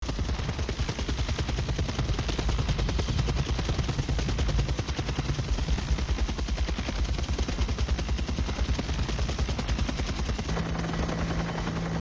Here's the exact same moment in the audio in these three synthesized channels:
-The Surround (Rear Channel) with the Dialogue Removed ! - (Pretty Cool, huh ?)